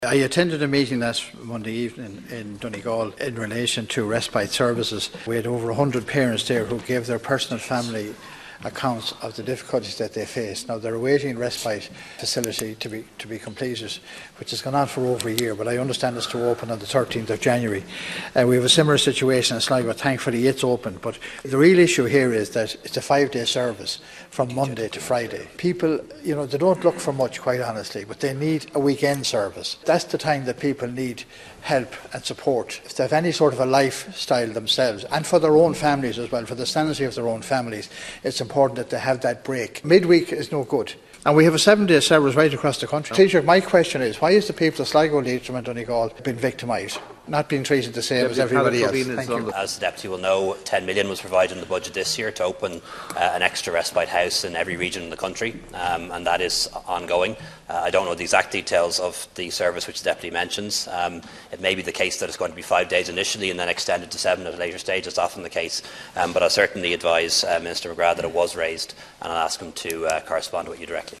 Deputy Eamon Scanlon was at that meeting, he says the North West is the only region that doesn’t have a weekend service…………….